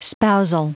Prononciation du mot spousal en anglais (fichier audio)
Prononciation du mot : spousal